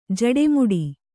♪ jaḍe muḍi